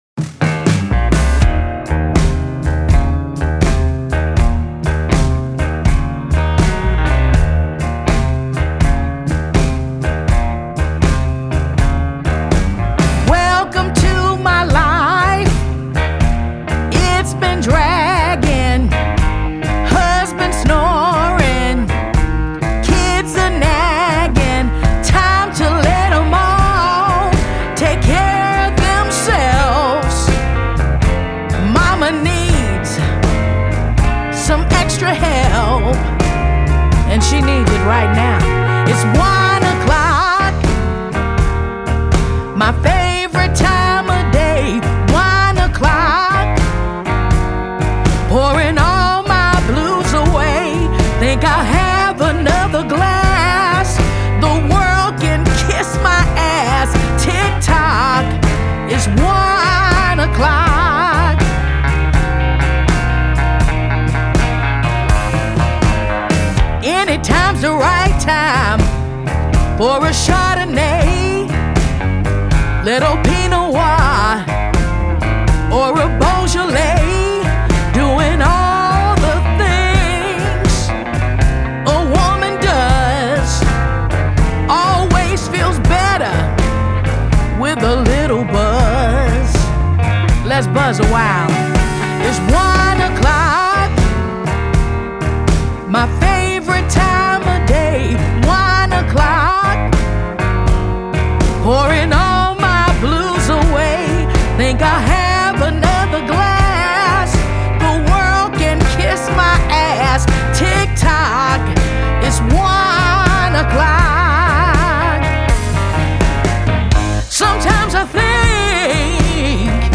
Текст хороший, веселый, легко берется на слух.